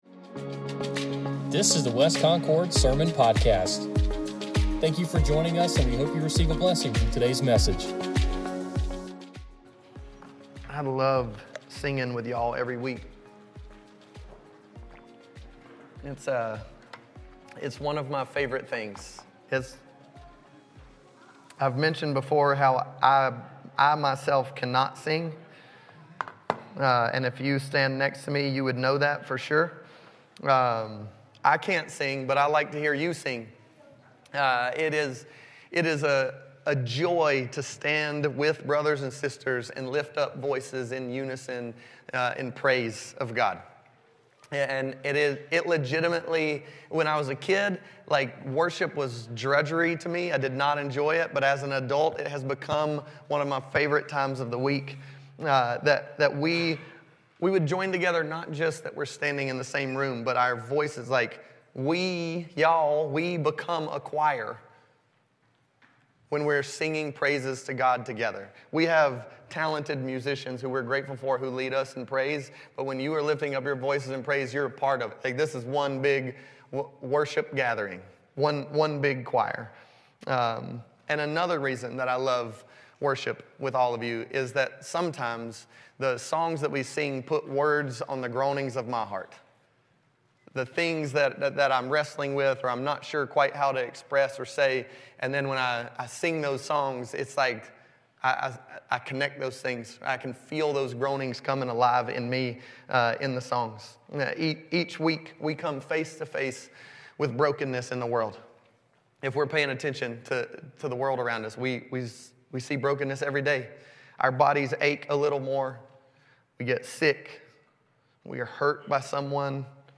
Download Download Reference Psalm 5 A Sermon Series in the Psalms It's ok to cry, because God cares and he will comfort you.